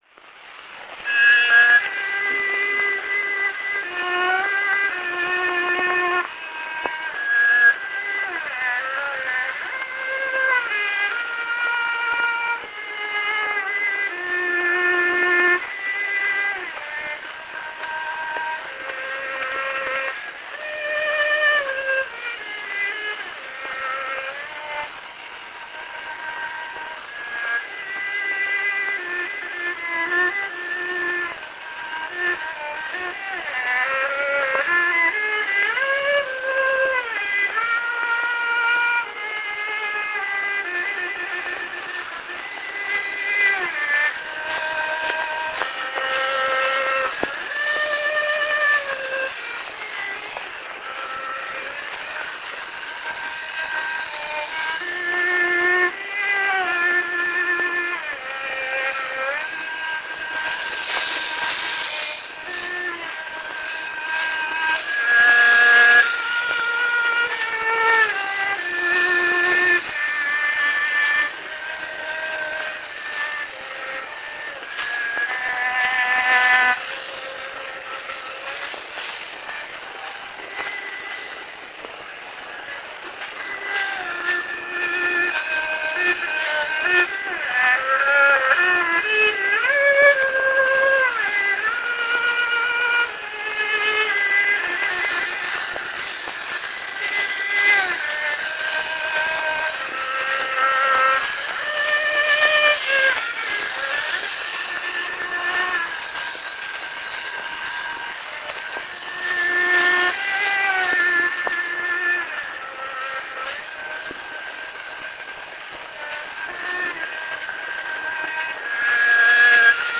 [Nocturne spillet på violin]
Ikke-komplet klaverkomposition spillet på solo-violin. Afbrydes da valsen løber ud. Violinisten kunne være Fini Henriques.
Rubenvalse